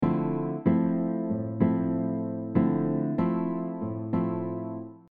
This mellow and beautiful style uses syncopated rhythms with a fingerpicking style of playing.
This pattern is the same as the previous except the bass note alternates to the 5th of chord on beat 3.
Basic bossa nova rhythm with alternating bass
Bossa-Nova-pattern-2.mp3